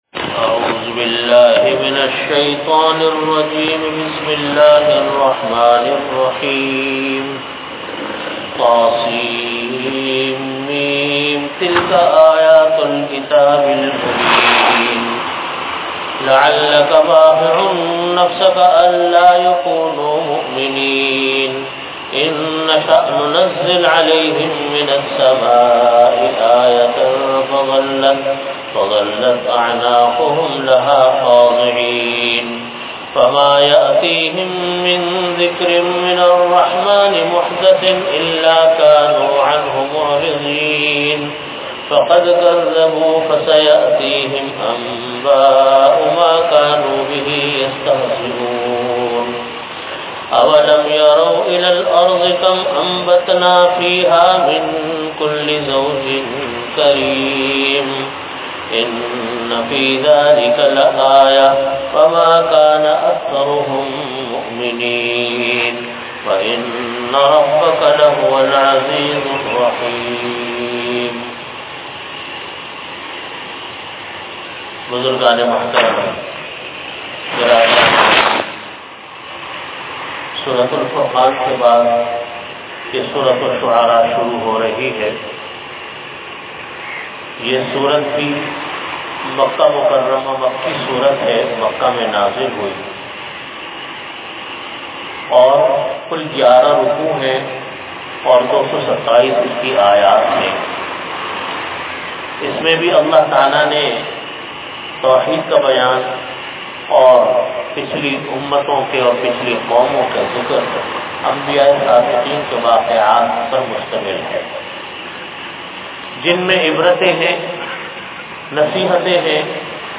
Audio Category: Tafseer
Time: After Asar Prayer Venue: Jamia Masjid Bait-ul-Mukkaram, Karachi